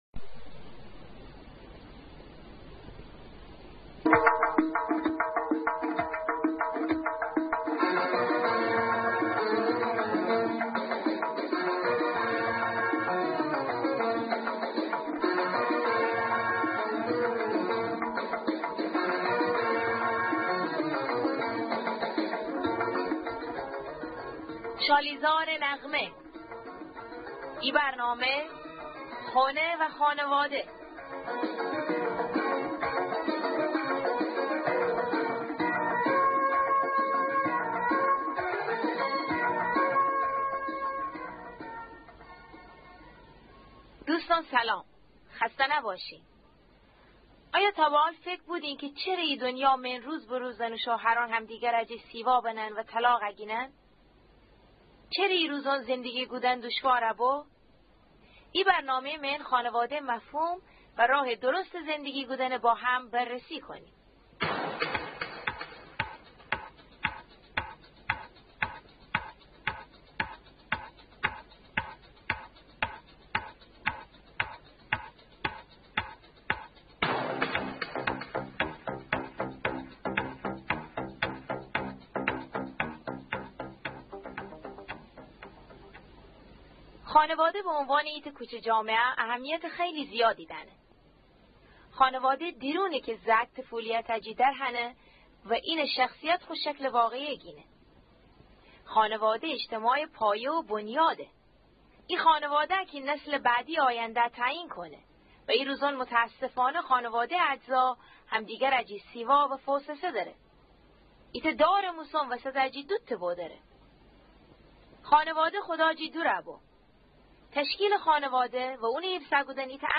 Radioprogramme in Gilaki - Heim und Familie (Lahijan-Dialekt) | Gilak Media